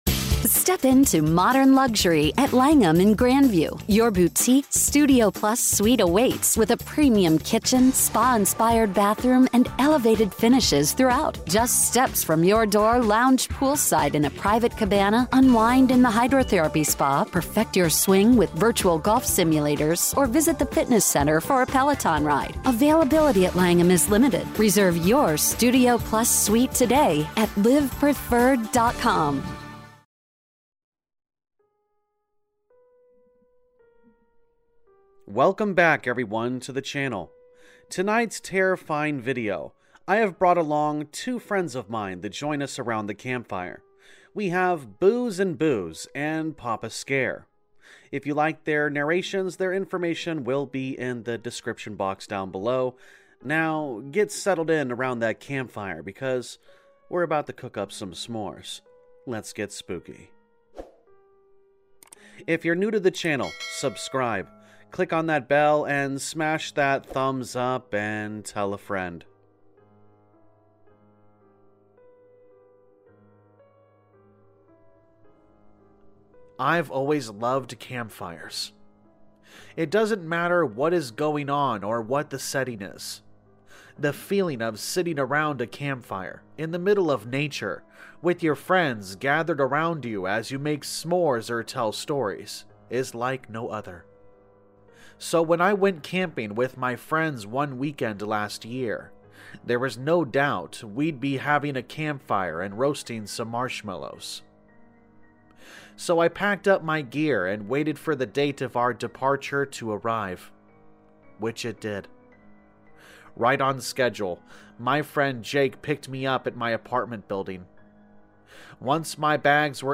Sound Effects Credits
All Stories are read with full permission from the authors